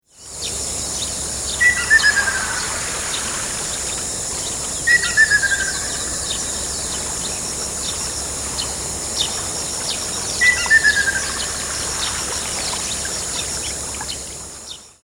川のせせらぎ、鳥のさえずり、波の音、虫の声、
気持ちの良い自然音と、カリンバ、サヌカイトなどの楽器、
水琴窟の響きなどをミックス。
ツグミ